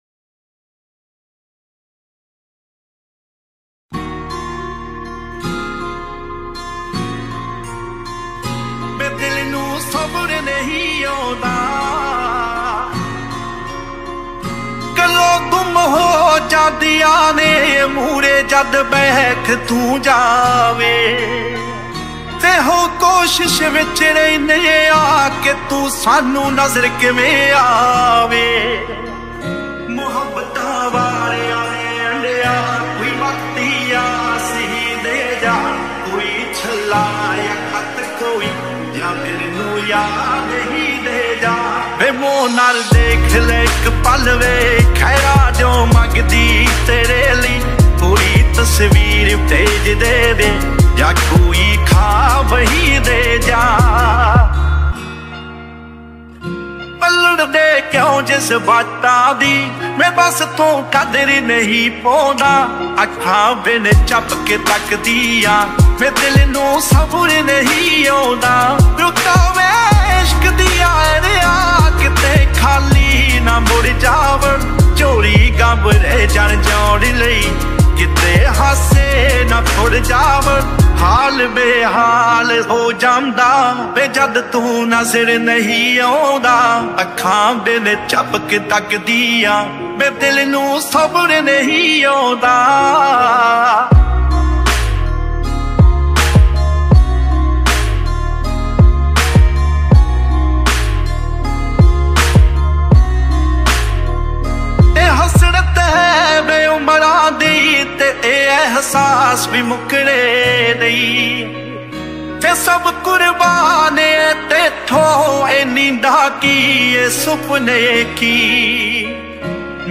New Punjabi Song